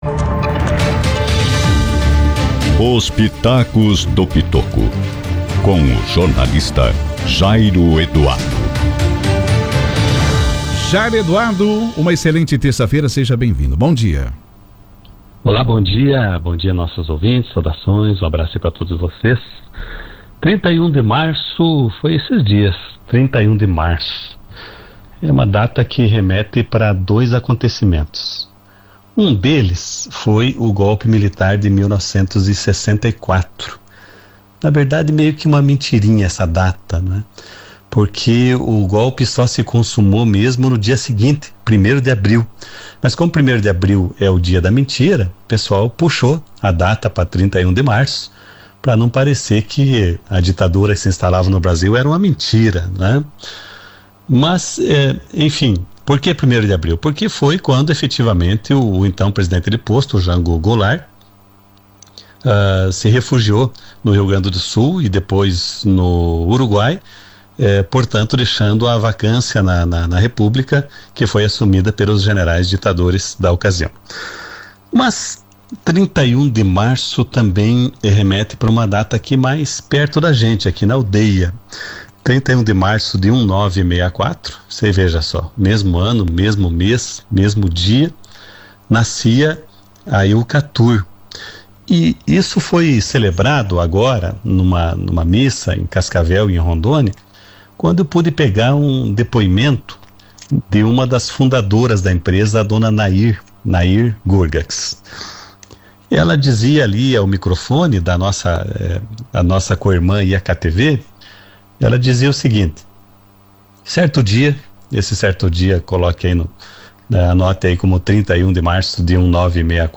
a análise editorial diária